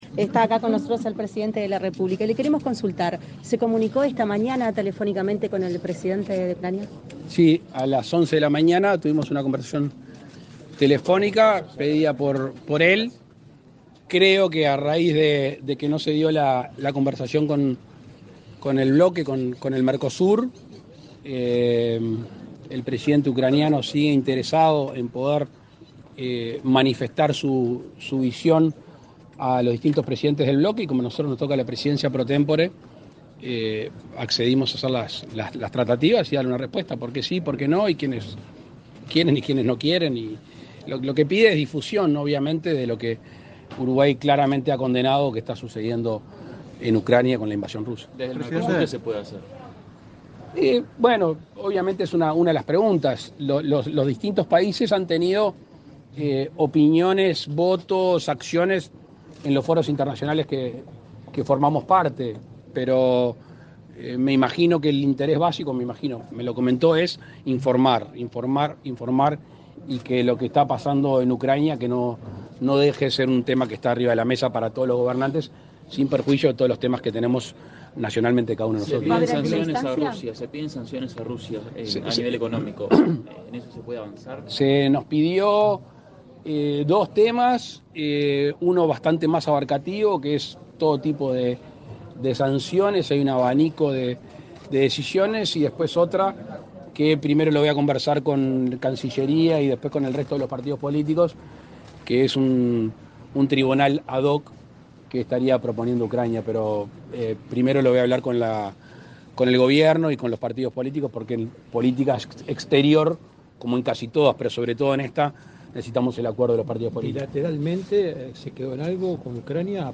Declaraciones a la prensa del presidente de la República, Luis Lacalle Pou
Declaraciones a la prensa del presidente de la República, Luis Lacalle Pou 26/07/2022 Compartir Facebook X Copiar enlace WhatsApp LinkedIn Tras participar en un almuerzo empresarial, organizado por el Banco República y la Unión de Exportadores del Uruguay (UEU), este 26 de julio, para celebrar el 50.° aniversario de esta agrupación, el presidente Luis Lacalle Pou efectuó declaraciones a la prensa.